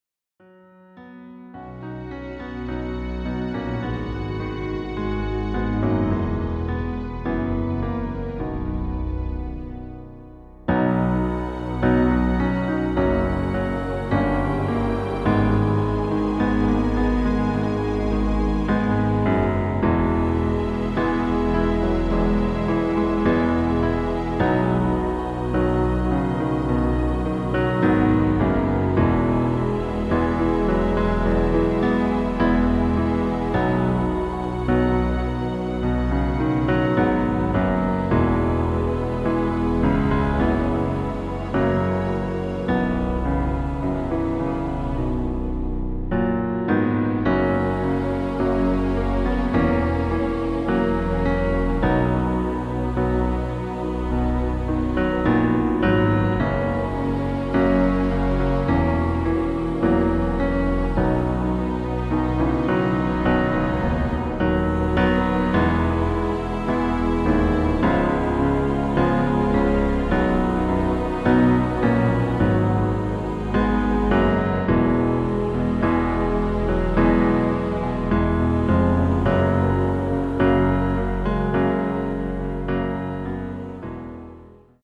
• Tonart: C, Cis, D, F, H
• Das Instrumental beinhaltet NICHT die Leadstimme